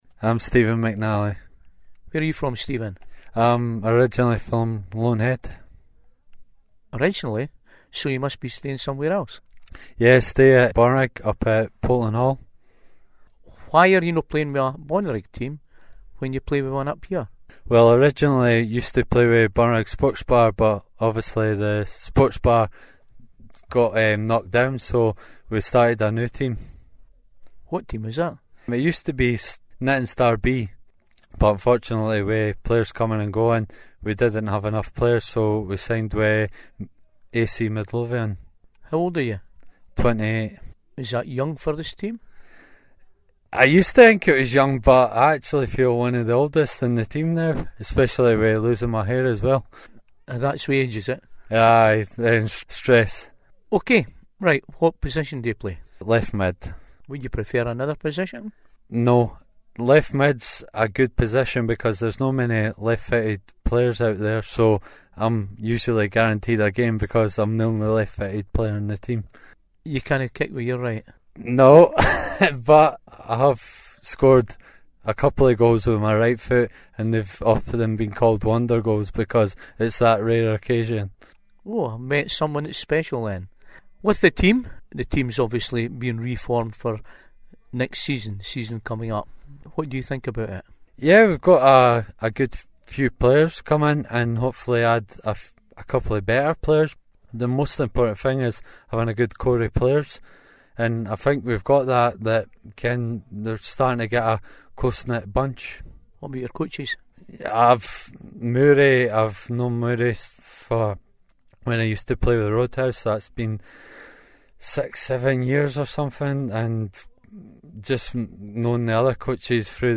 AC Midlothian Presentation Evening